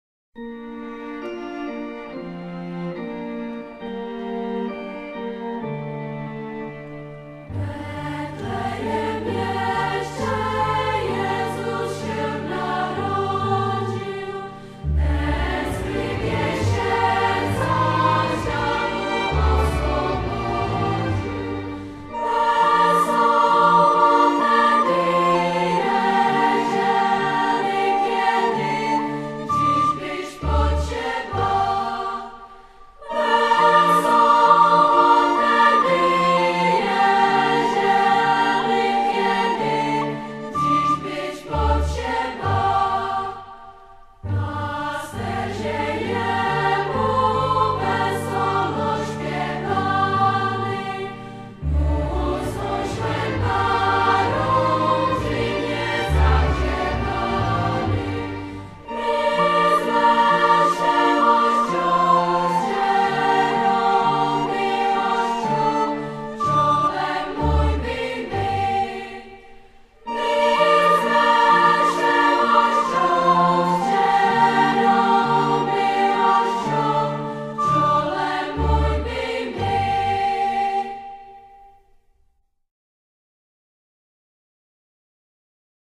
Repertoár - nahrávky z koncertů